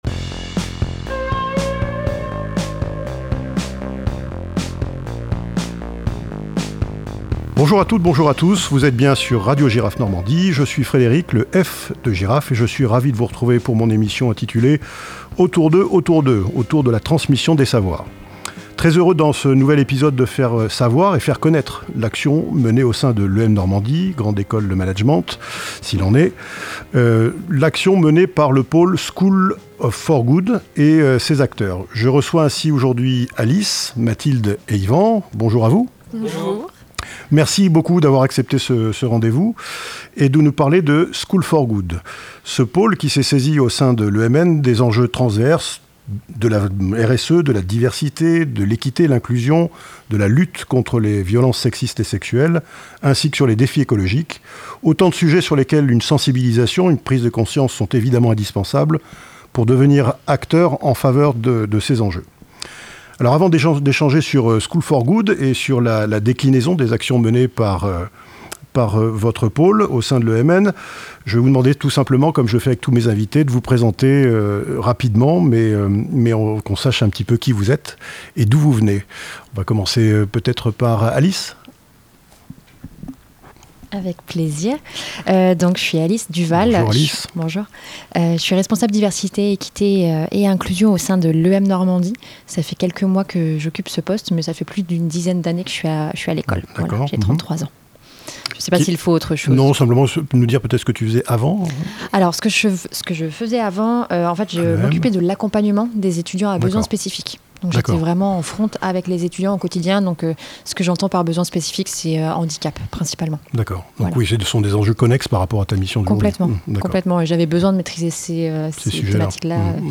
Entretiens croisés passionnants, concrets et éclairants!